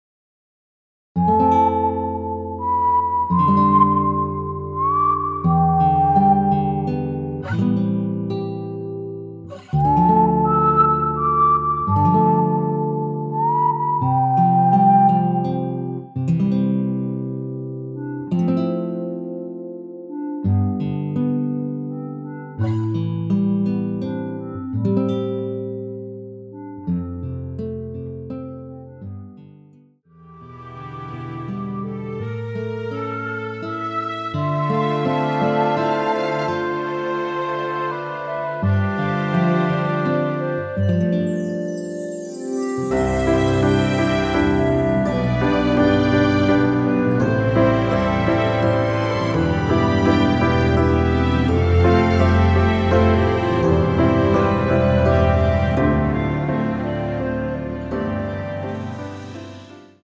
무반주 구간 들어가는 부분과 박자 맞출수 있게 쉐이커로 박자 넣어 놓았습니다.(일반 MR 미리듣기 참조)
앞부분30초, 뒷부분30초씩 편집해서 올려 드리고 있습니다.
중간에 음이 끈어지고 다시 나오는 이유는